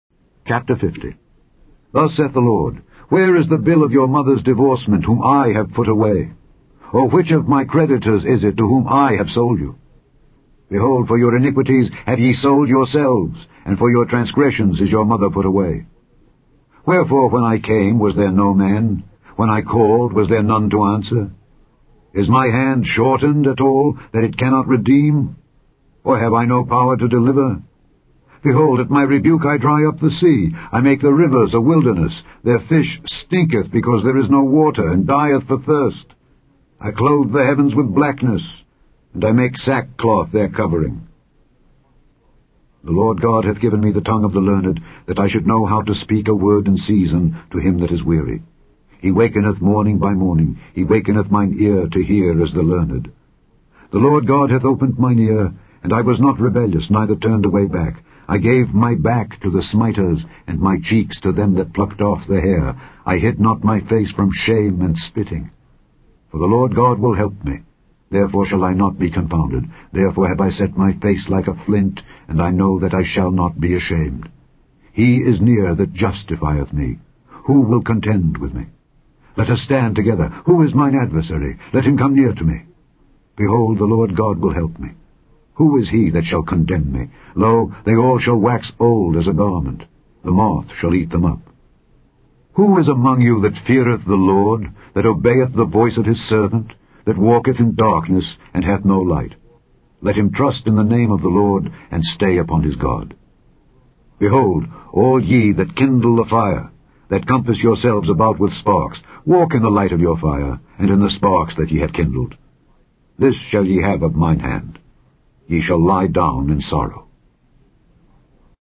This Week's Daily Bible Reading